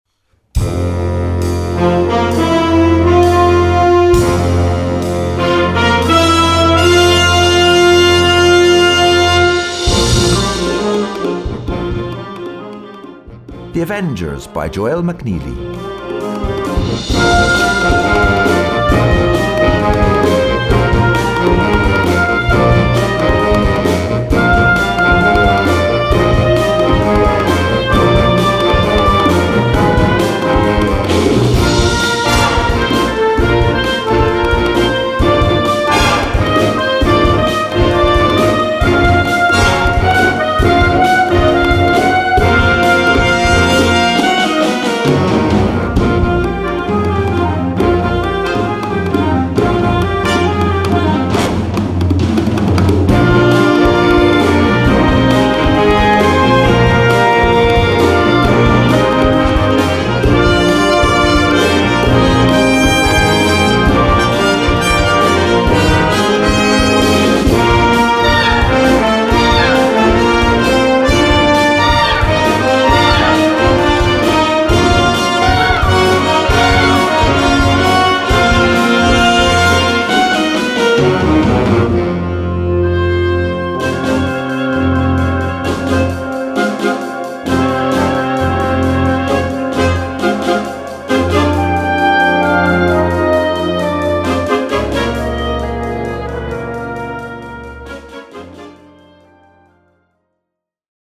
Gattung: Filmmusik
A4 Besetzung: Blasorchester PDF